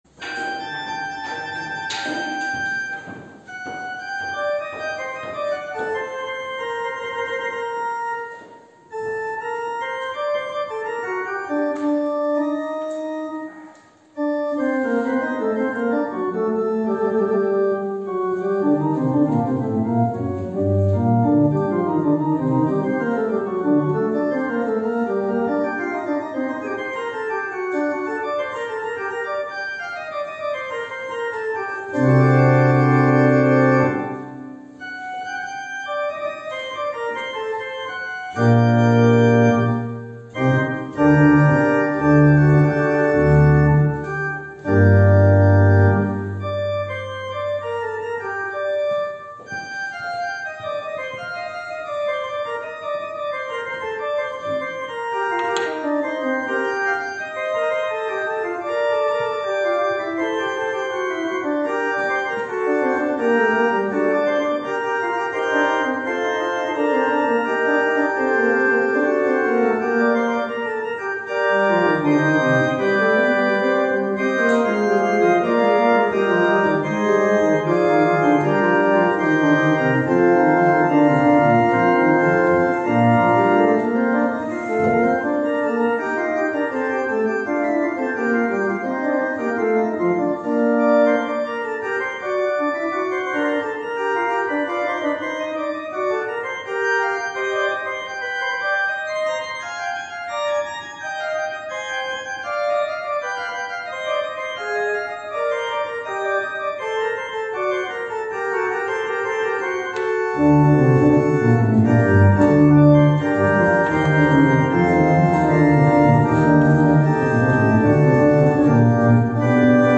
Gottesdienst aus der reformierten Erlöserkirche,
Orgelvorspiel